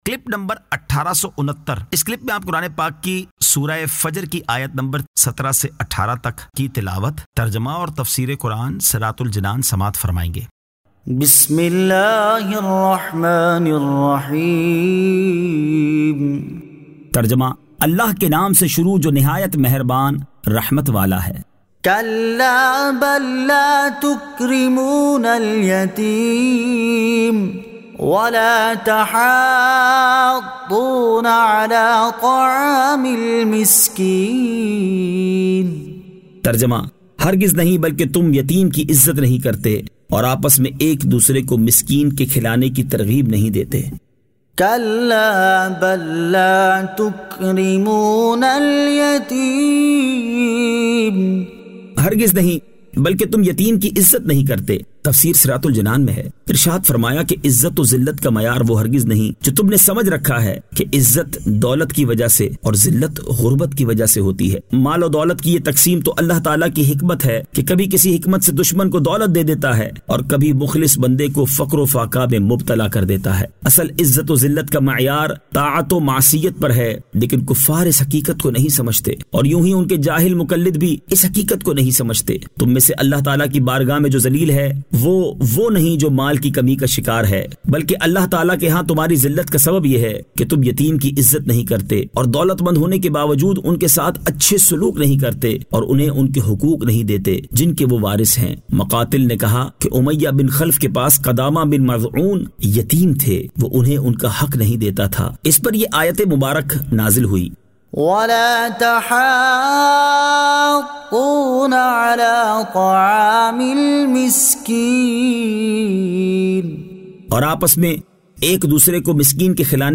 Surah Al-Fajr 17 To 18 Tilawat , Tarjama , Tafseer